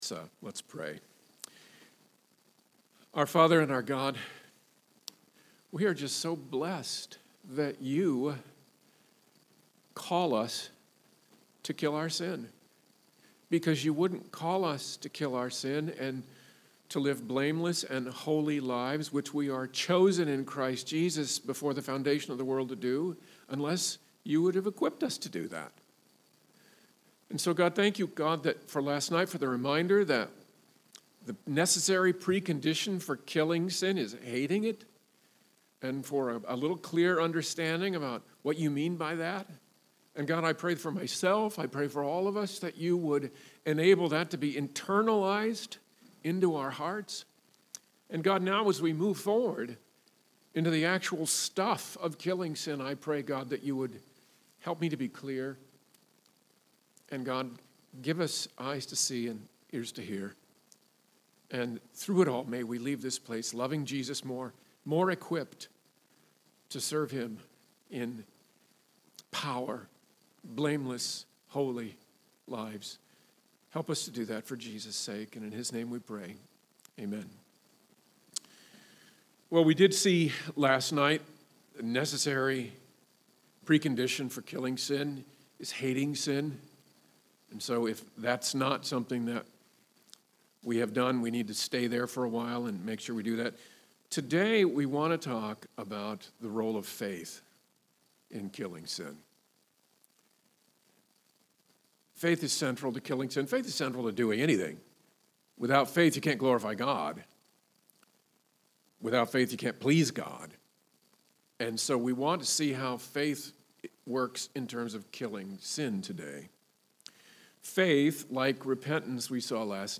Men's Retreat Talks | The Landing Church
Sermon Notes